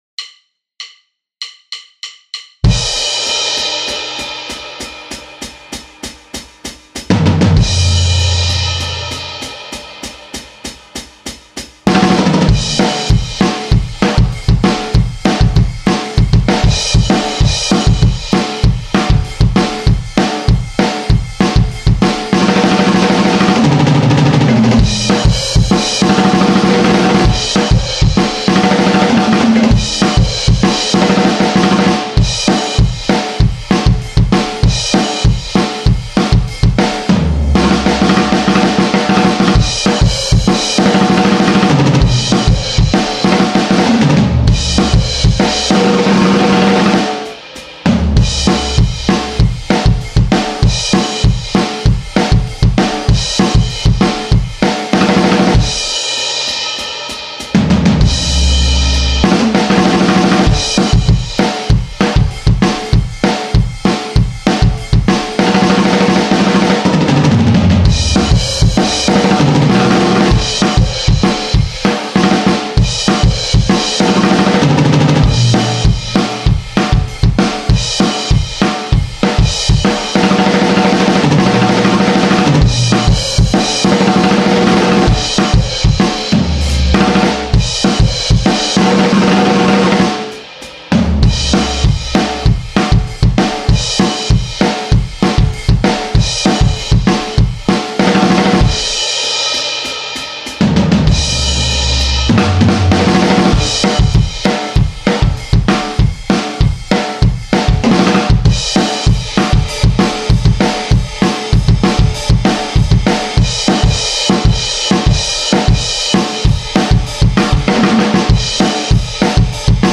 Rock Kit